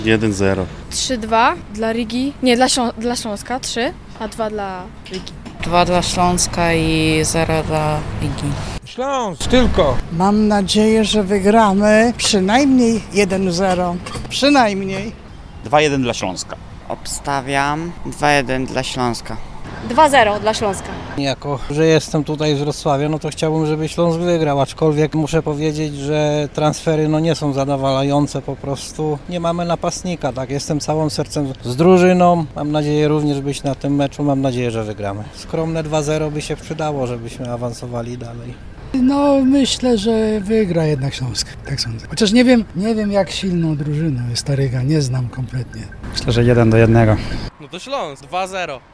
Jakim wynikiem zakończy się to niezwykle ważne spotkanie? Zapytaliśmy mieszkańców stolicy Dolnego Śląska o ich typy.